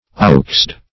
Oxeyed \Ox"*eyed`\, a. Having large, full eyes, like those of an ox.